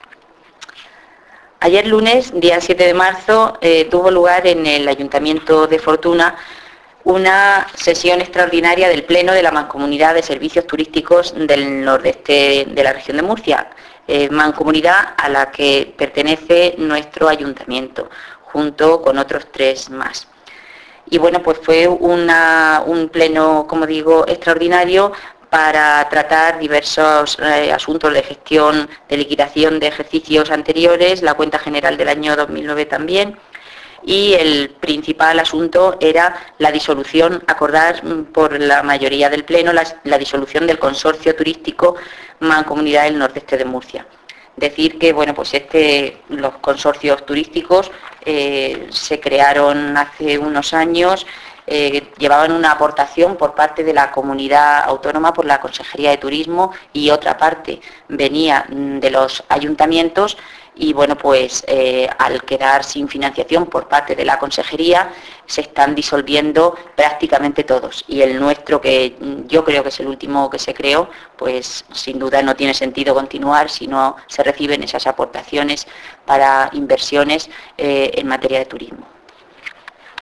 Descargar: La concejal de Turismo habla de esta reunión subir